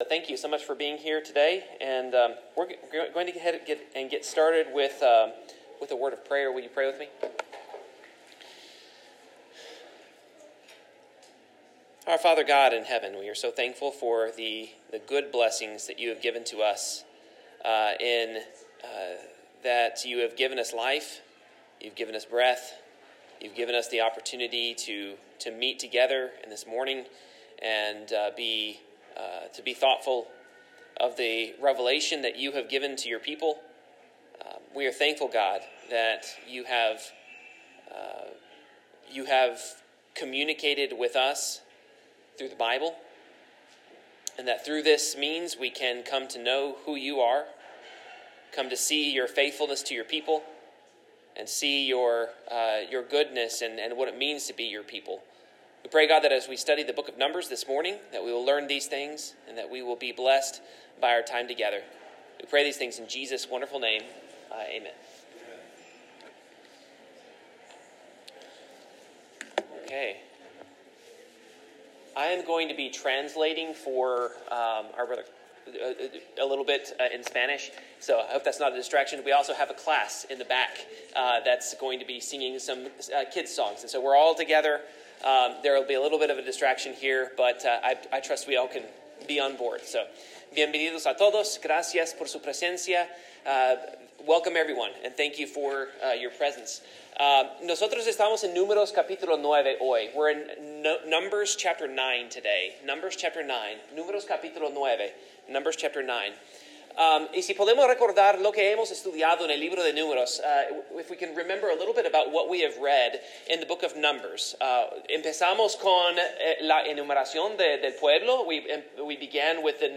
Numbers 9-10 Service Type: Bible Class In Numbers 9-10